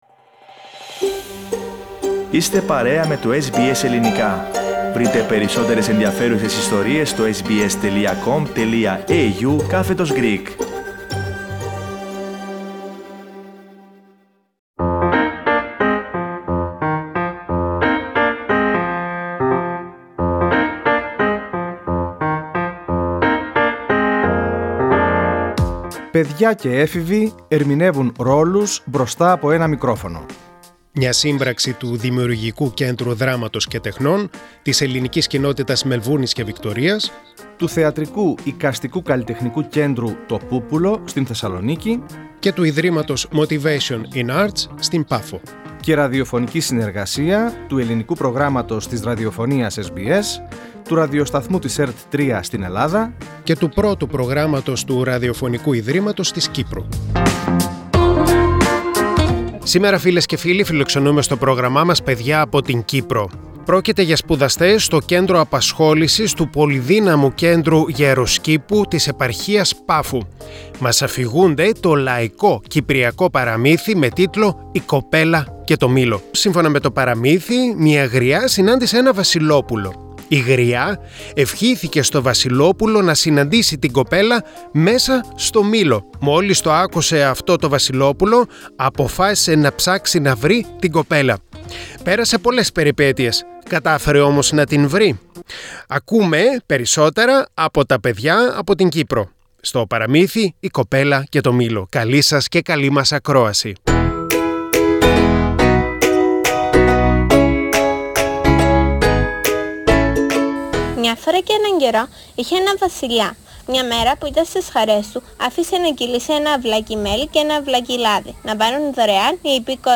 Kids' Radio Theatre